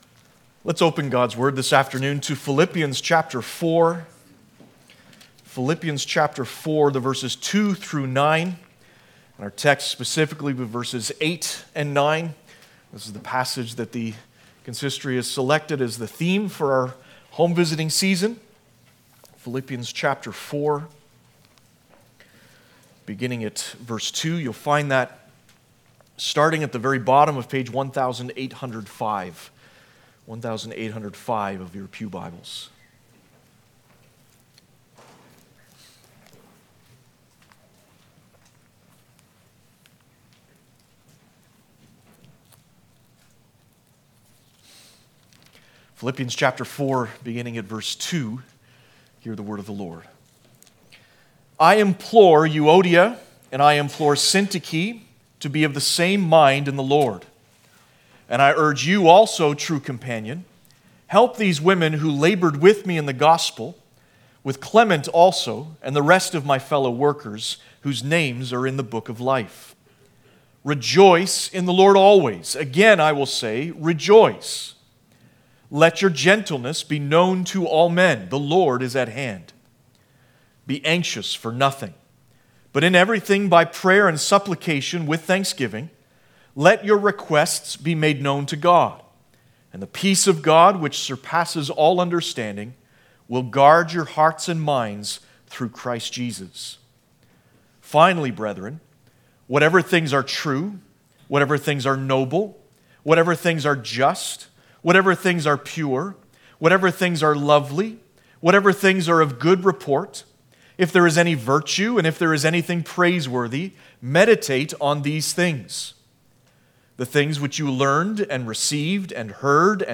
Home Visiting Theme Passage: Philippians 4:8-9 Service Type: Sunday Morning « Jesus Said